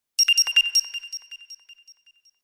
Buzzer.ogg